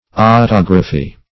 Otography \O*tog"ra*phy\, n.